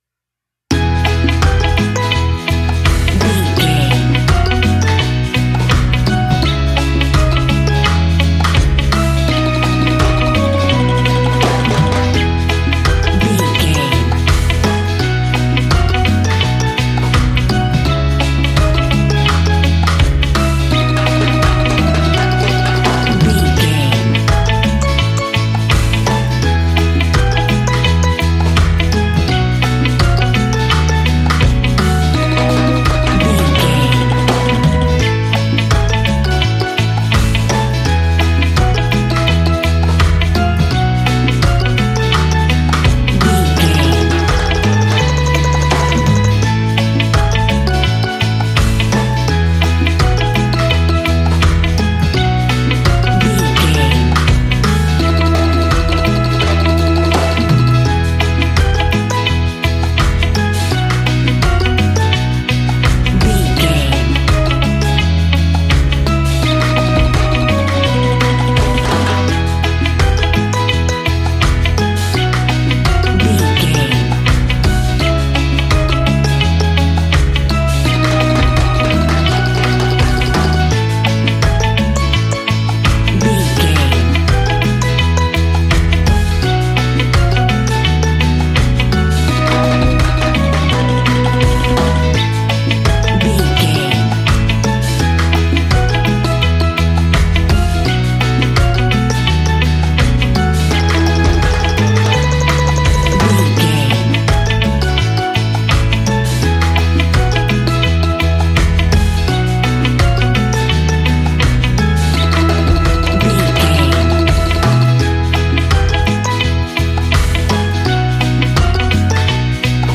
Ionian/Major
Slow
steelpan
worldbeat
happy
drums
percussion
bass
brass
guitar